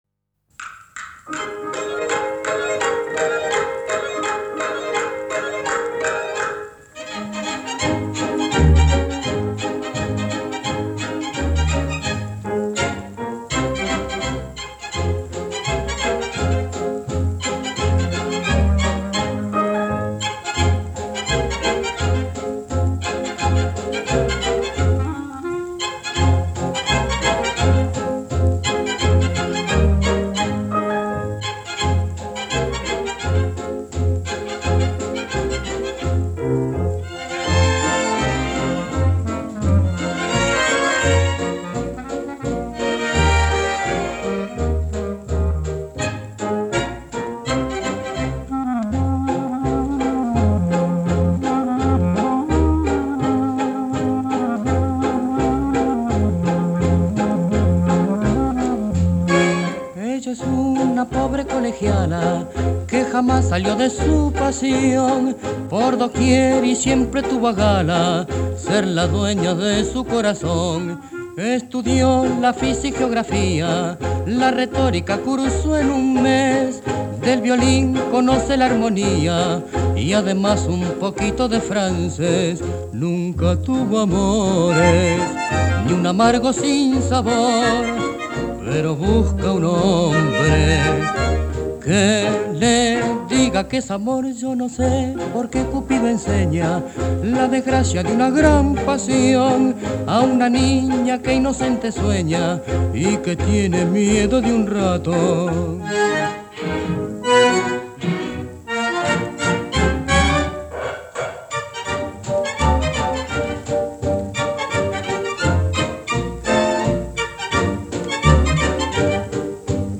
Галерея Любимые милонги